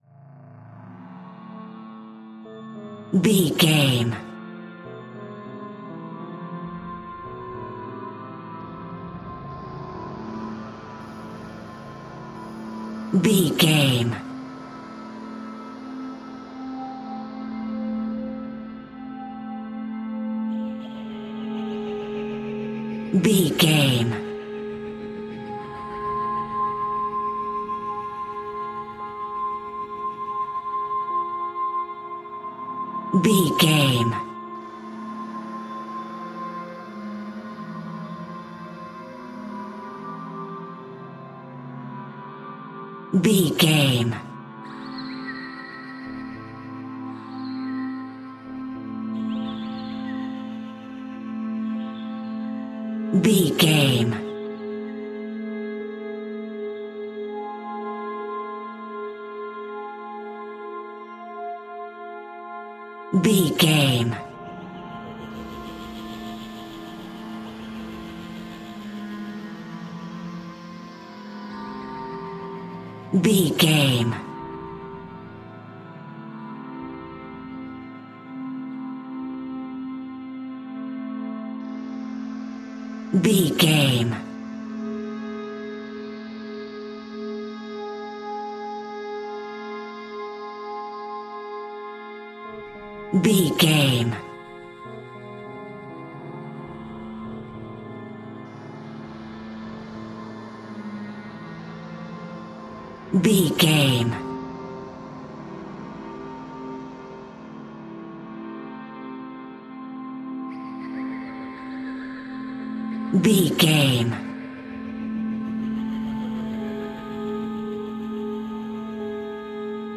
Thriller
Aeolian/Minor
Slow
tension
ominous
haunting
eerie
synthesiser
keyboards
ambience
pads
eletronic